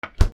冷蔵庫 閉める
『バタン』